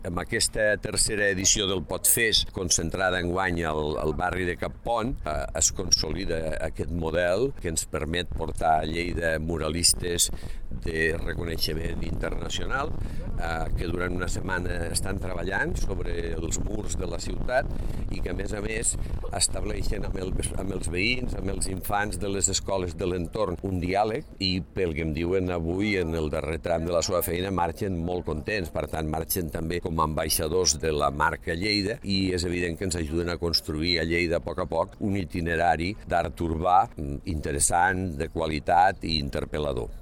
Així mateix, també ha remarcat el que el PotFest ofereix a la ciutat de Lleida. Tall de veu .